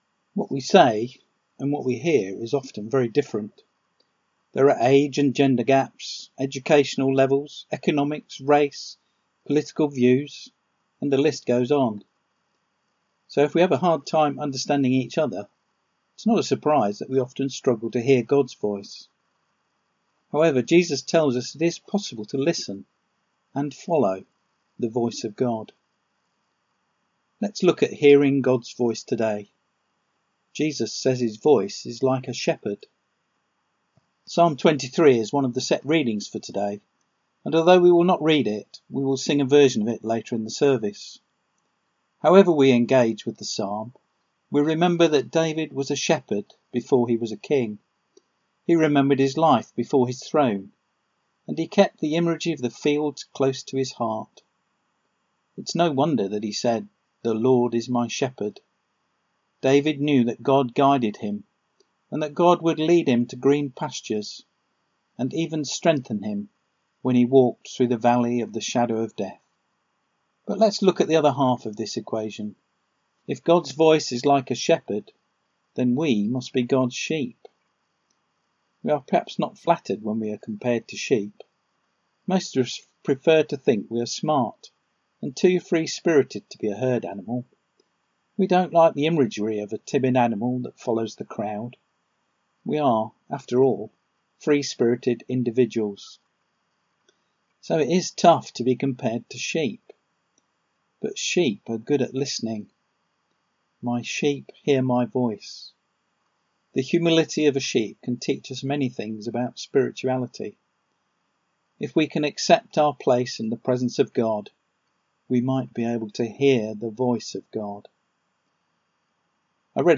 A silly sketch which came to me. Something to make you think perhaps.